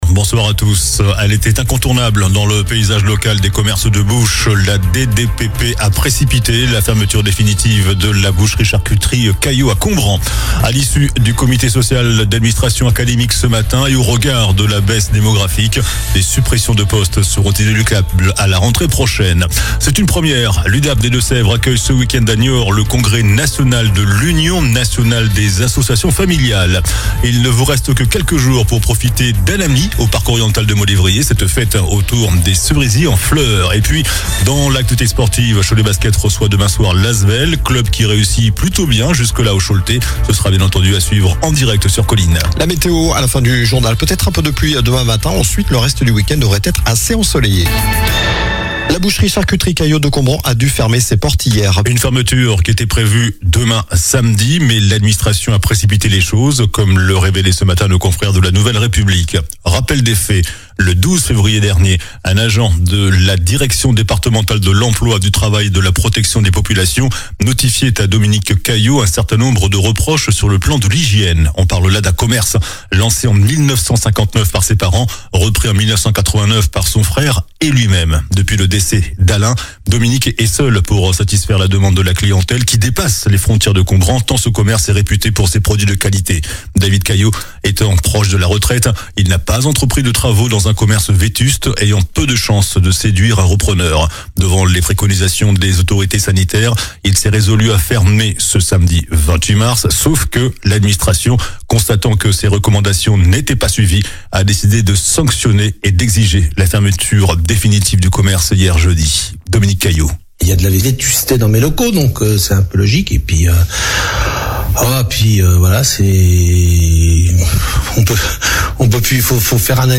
JOURNAL DU VENDREDI 27 MARS ( SOIR )